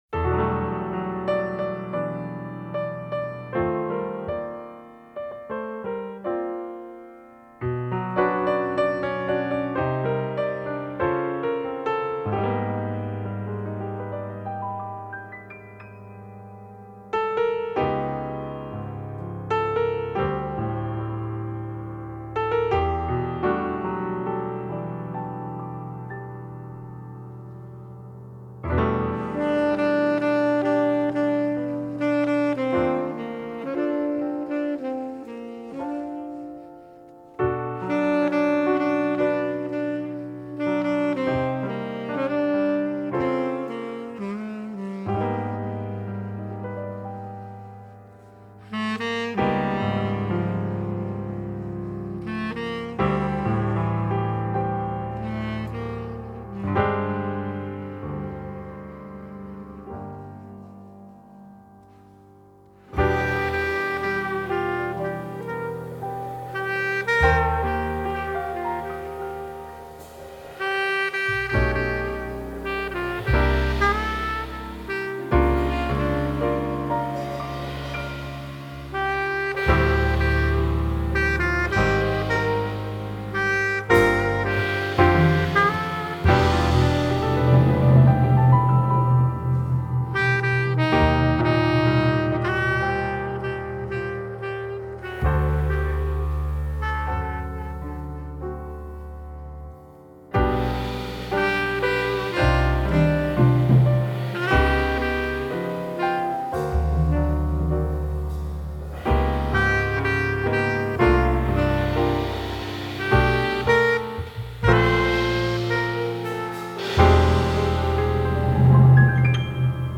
piano
tenor saxophone
bass
drums